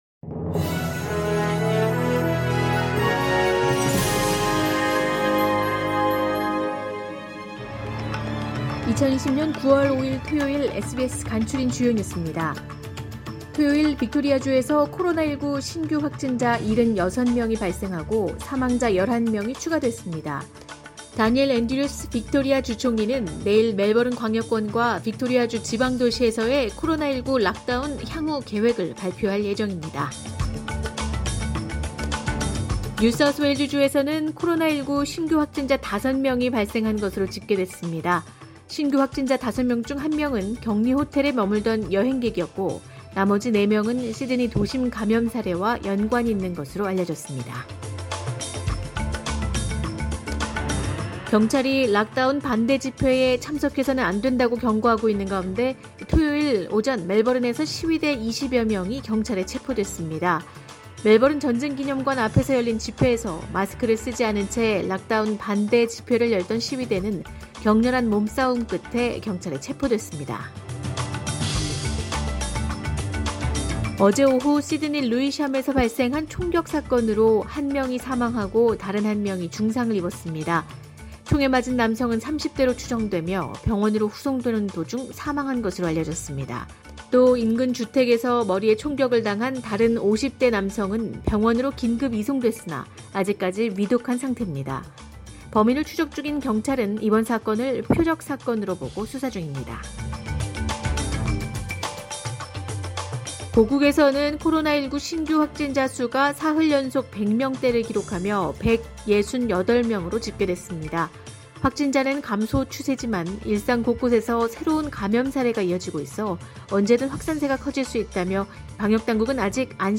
SBS 한국어 뉴스 간추린 주요 소식 – 9월 5일 토요일